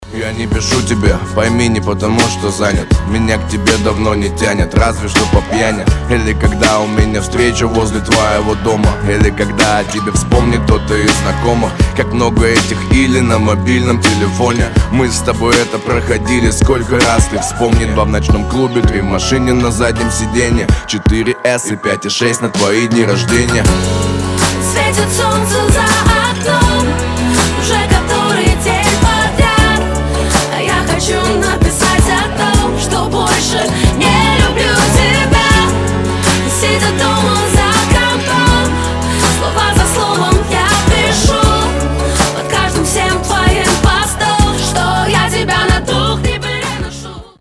лирика
Хип-хоп
грустные